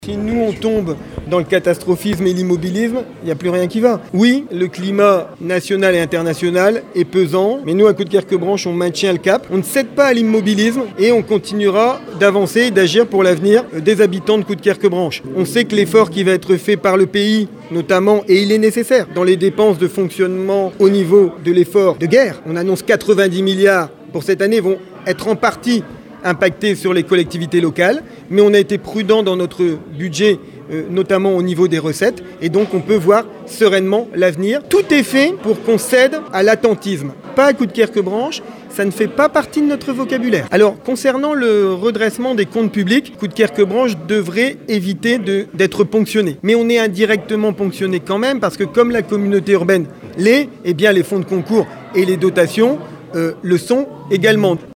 Le maire l’assure, les finances vont bien.
Le maire de Coudekerque-Branche, David Bailleul.